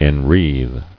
[en·wreathe]